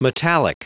Prononciation du mot metallic en anglais (fichier audio)
Prononciation du mot : metallic